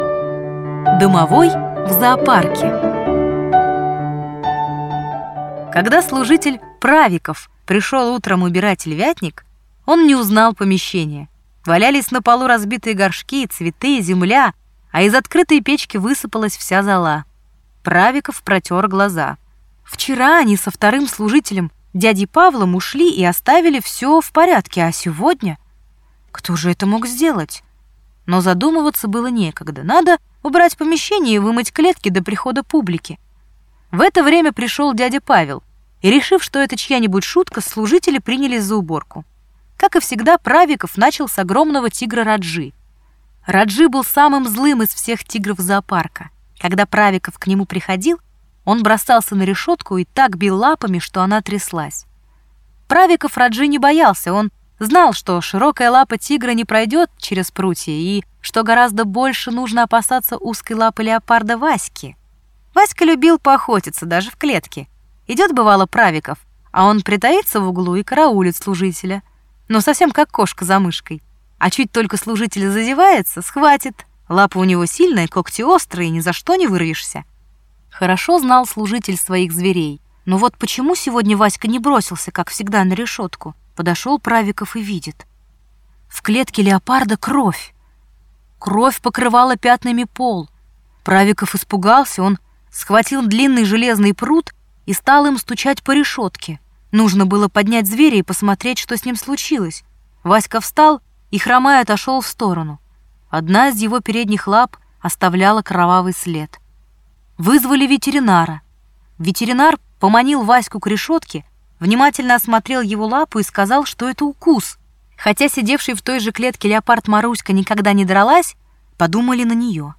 Аудиорассказ ««Домовой» в зоопарке»